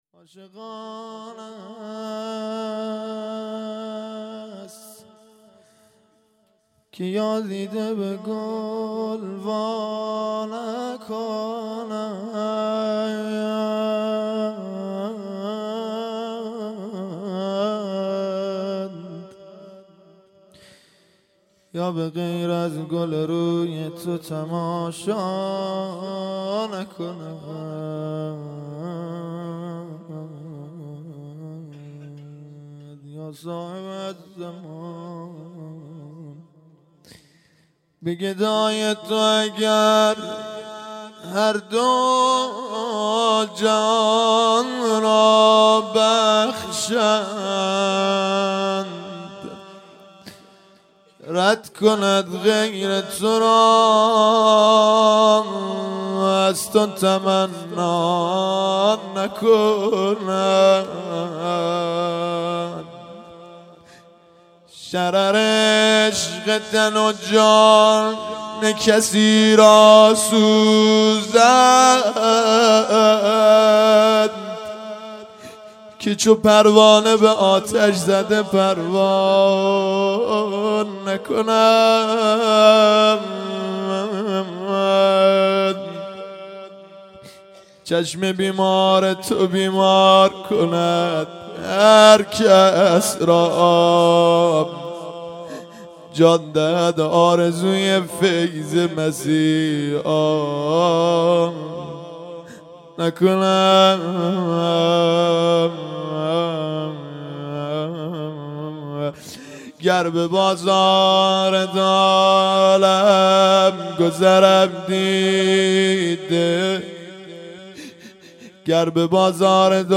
صوت های مراسم شهادت امام زین العابدین(ع)
مناجات پایانی